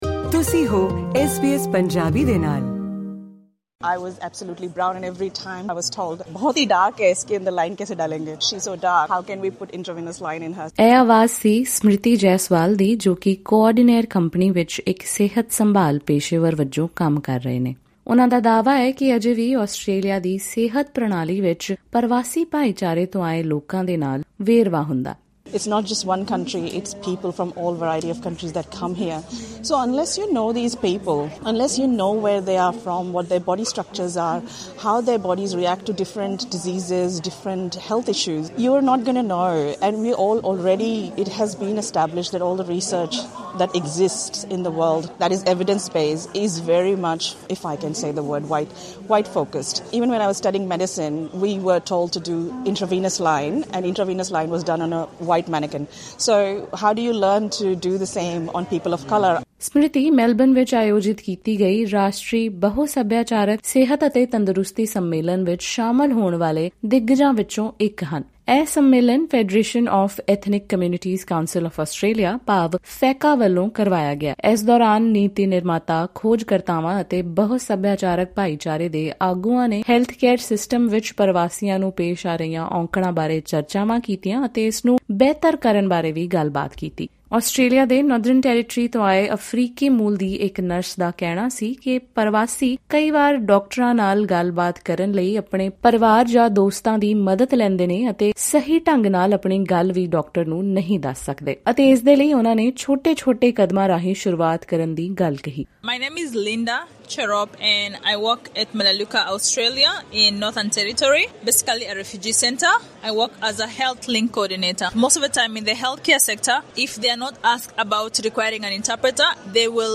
A migrant from India recalls her experience of being told off by a healthcare professional, as they claimed that they did not know how to insert an IV in her skin, as they were unable to locate veins in her dark skin colour.